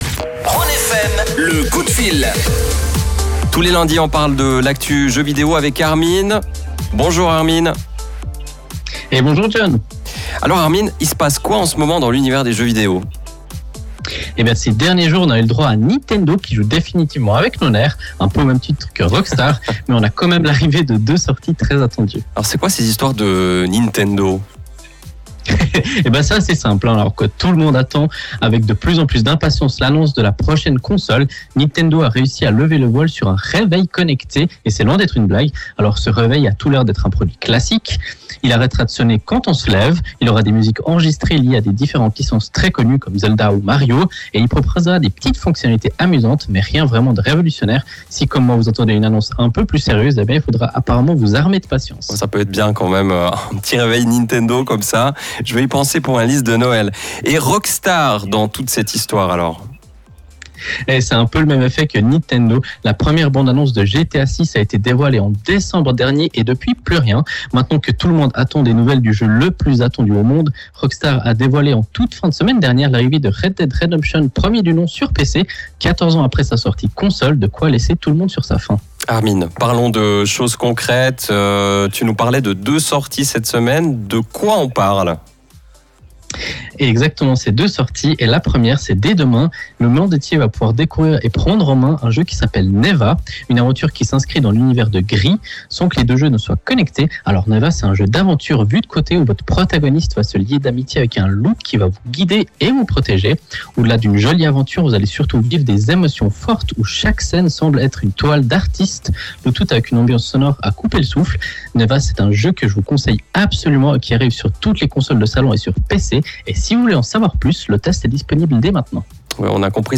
Comme tous les lundis, nous avons la chance de présenter notre chronique gaming sur la radio Rhône FM.
Vous pouvez réécouter le direct Rhône FM via le flux qui se trouve juste en haut de l’article.